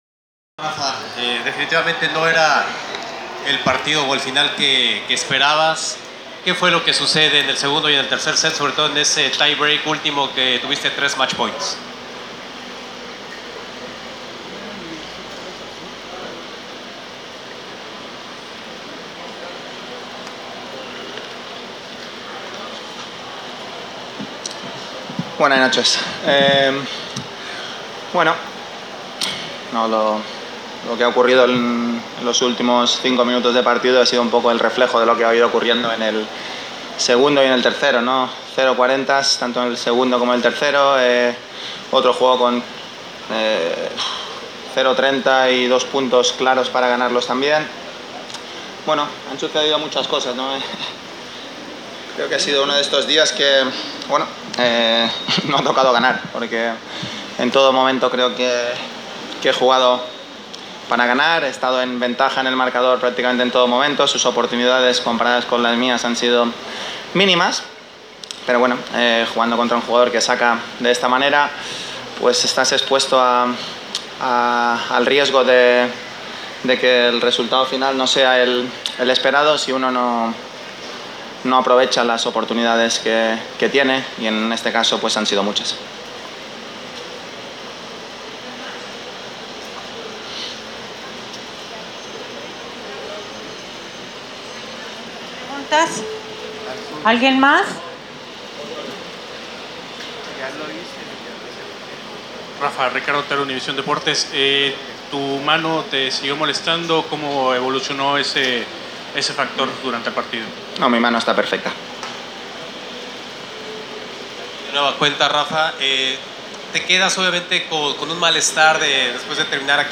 Press Conference – Rafael Nadal (27/02/2019)